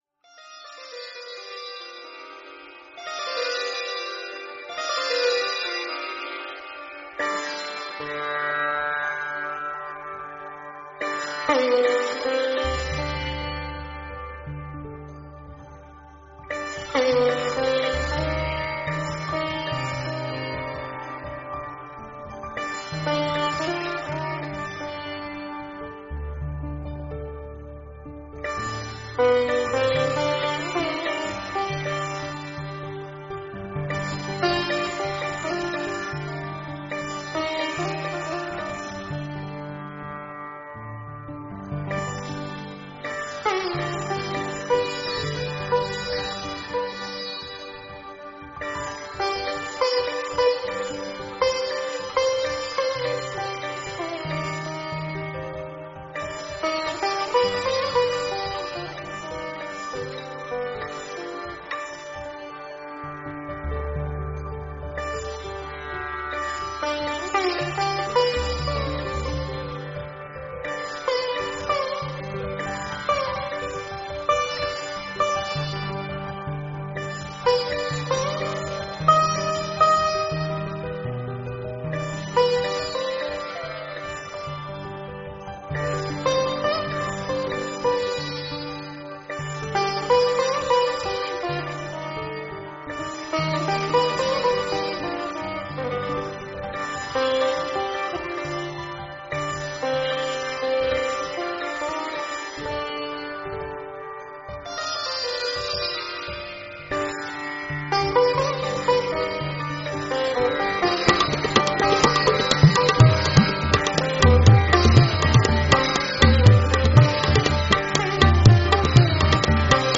Indian-Classical.mp3